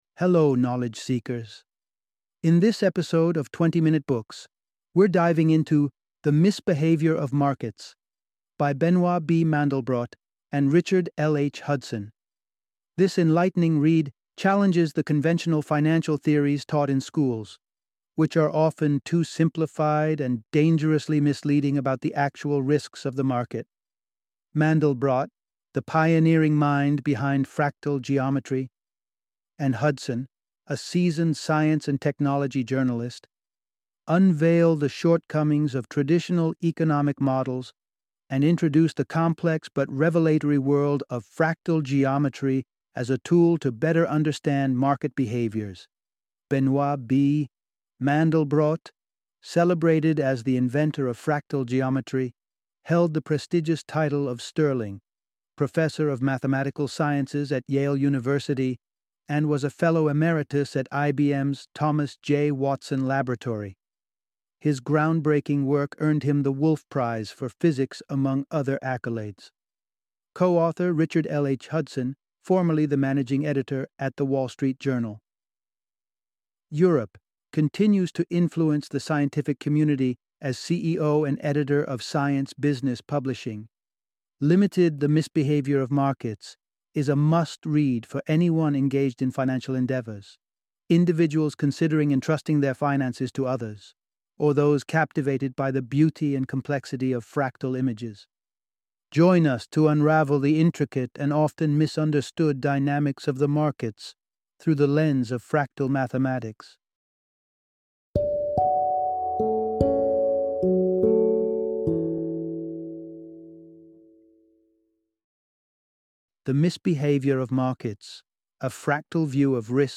The Misbehavior of Markets - Audiobook Summary